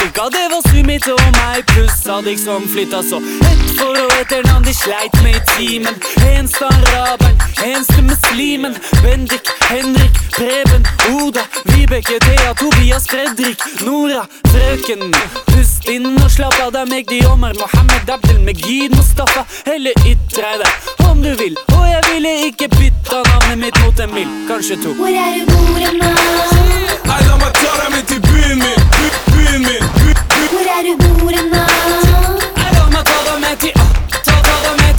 Жанр: Хип-Хоп / Рэп
Hip-Hop, Hip-Hop, Rap